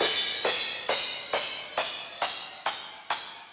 他にはシンバルの「ｼｬｰﾝ」という音やシェイカーの「ｼｬｯｼｬｶ」といった音もこれに似た周波数成分になっていて、ピッチ感はかなり希薄です。だからこの手のパーカッションの音をチューナーにかけても、何も反応してくれません。
P-cymbal-pitch.mp3